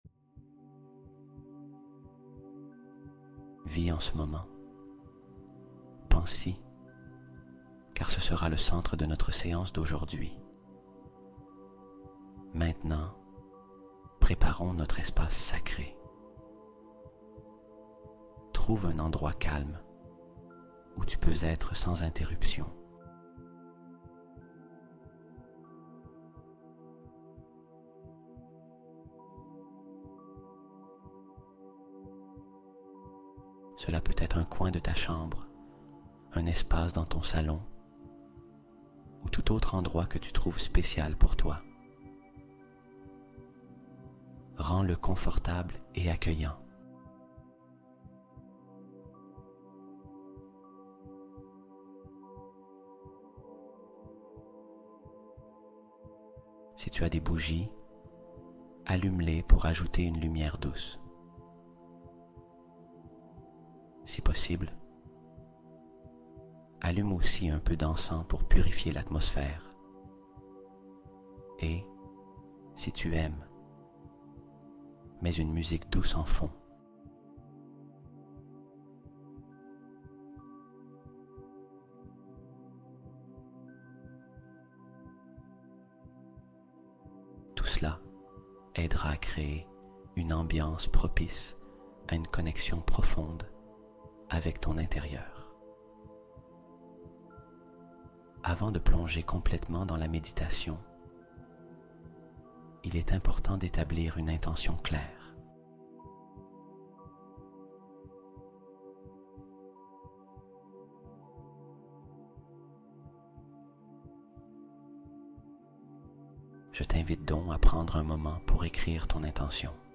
1111Hz ALERTE DIVINE ACTIVÉE | Miracles + Guérison + Amour Arrivent Dans Les Prochaines 48 Heures